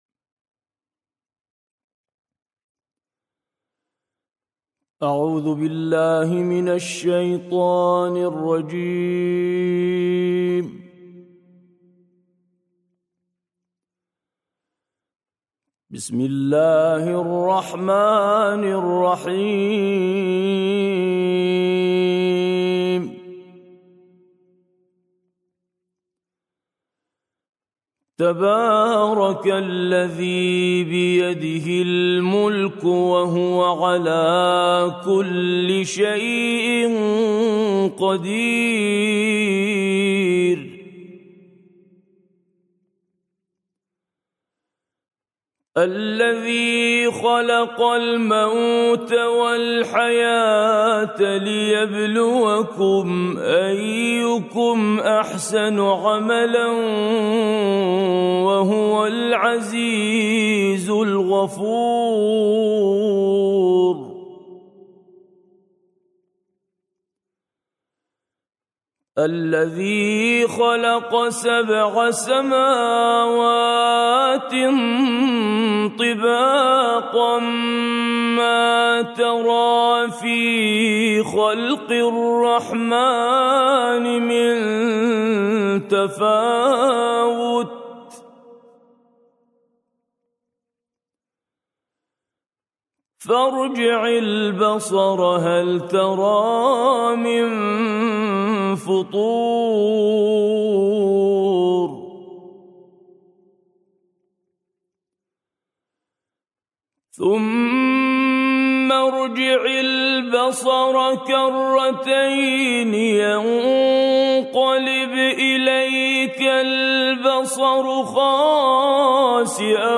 سورة الملك - بالطور العراقي - لحفظ الملف في مجلد خاص اضغط بالزر الأيمن هنا ثم اختر (حفظ الهدف باسم - Save Target As) واختر المكان المناسب